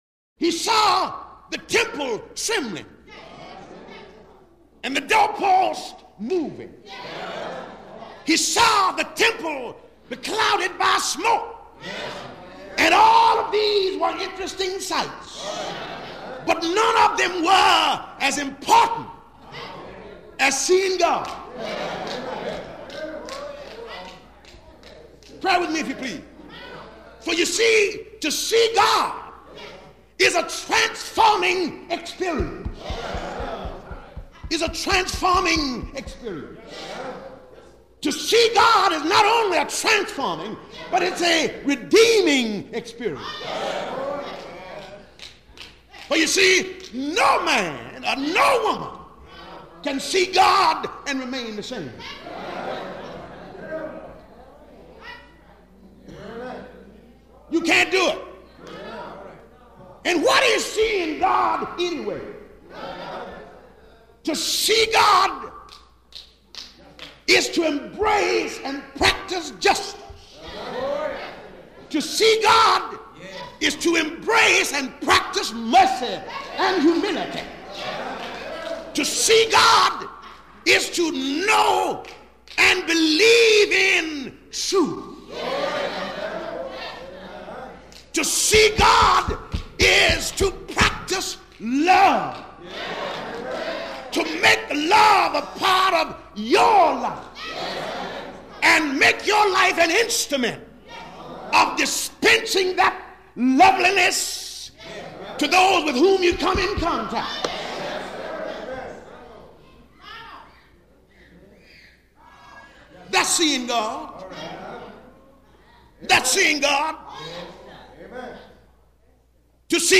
A Sermon by Rev. C. L. Franklin